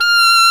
Index of /90_sSampleCDs/Roland LCDP07 Super Sax/SAX_Alto Short/SAX_Pop Alto
SAX D#5 S.wav